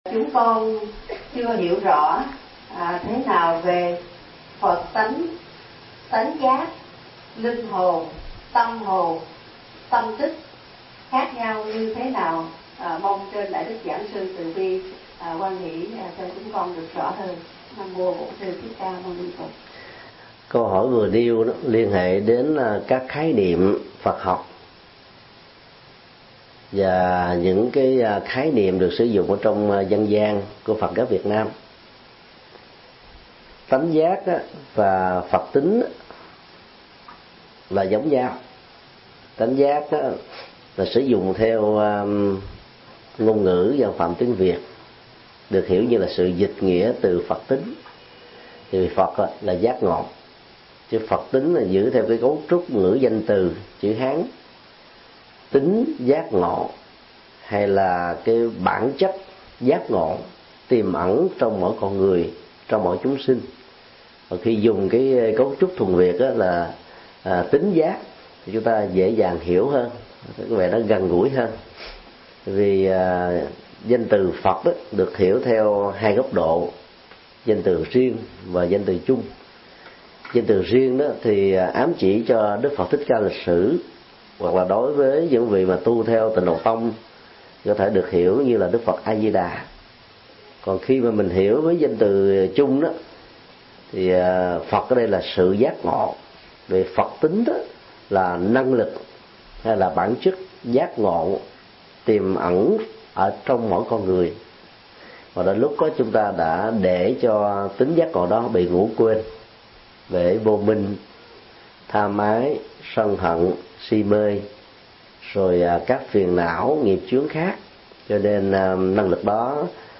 Mp3 Vấn đáp: Khái niệm Phật tính-tâm tính,tâm linh -linh hồn-hương linh